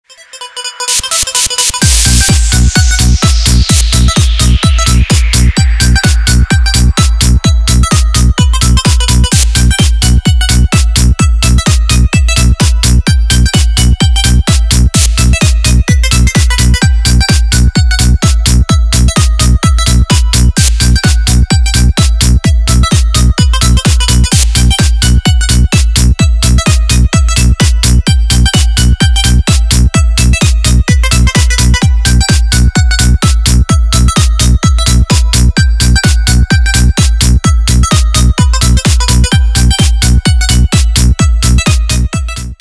играет Громкие звонки, звучные рингтоны🎙